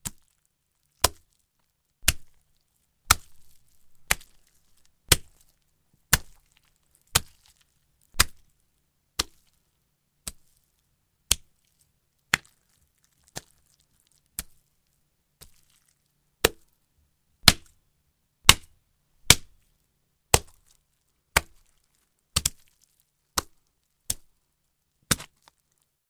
Звук втыкания ножа или вилки в мясо